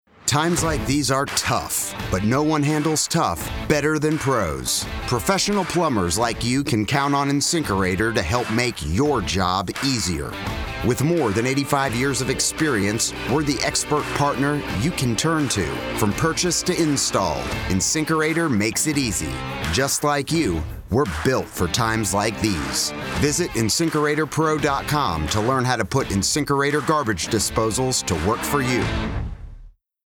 TimesLikeThese-AudioAd.mp3